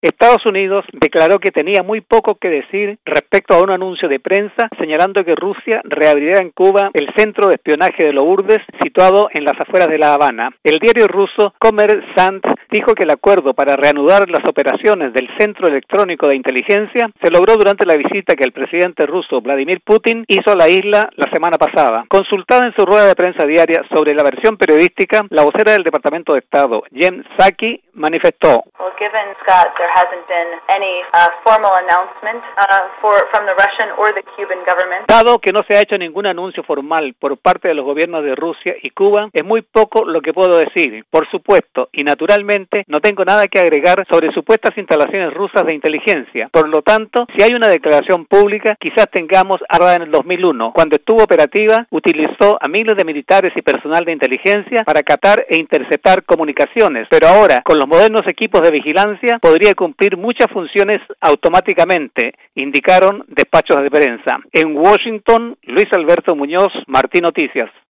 Embed share Desde Washington